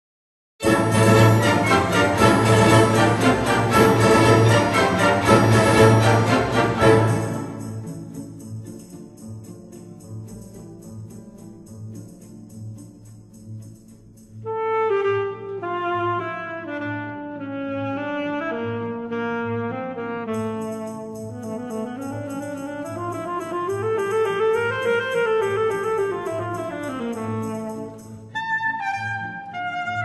Allegro vivo 2:50